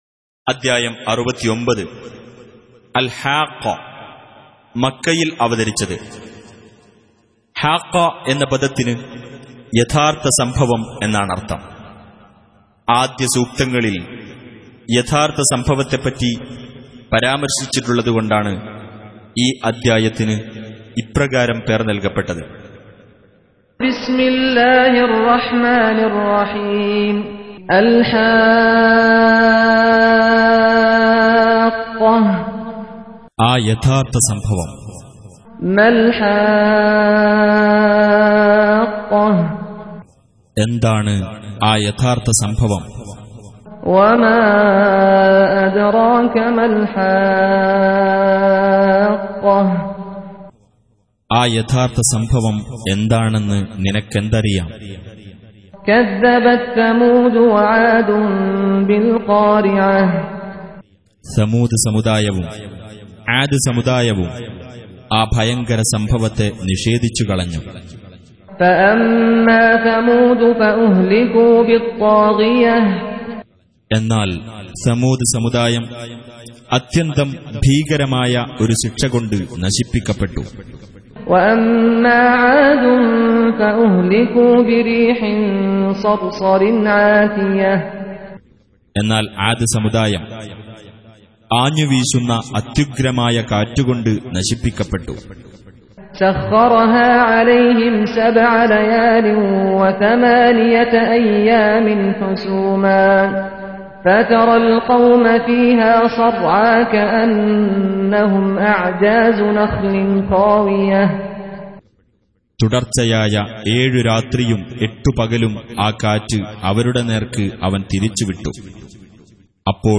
Surah Repeating تكرار السورة Download Surah حمّل السورة Reciting Mutarjamah Translation Audio for 69.